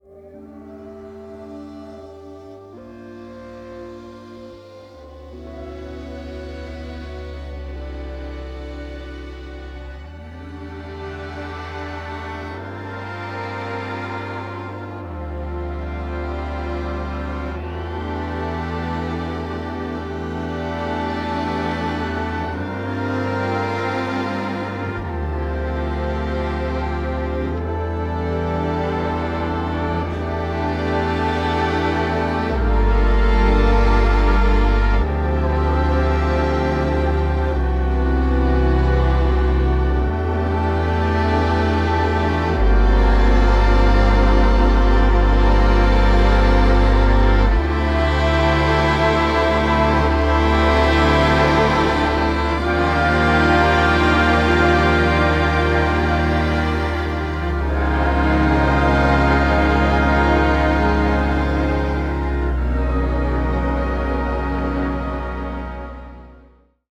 stunning orchestral works